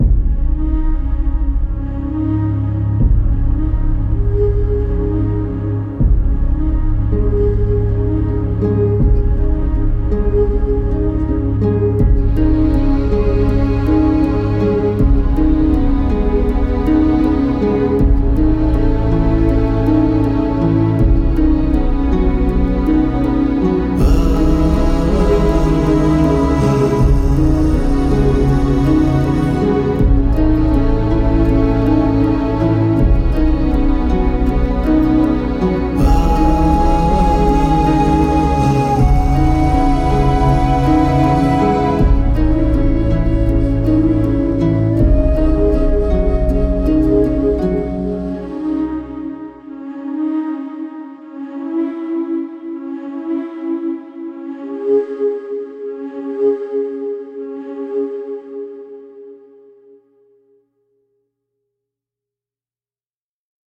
hauntingly, beautiful score
kora
stirring and evocative chants/vocals
excerpts from select score cues